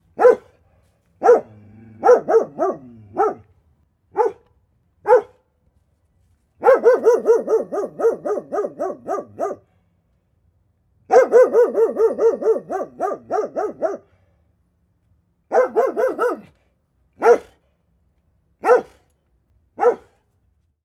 dog-barking-2-bullmastiff.mp3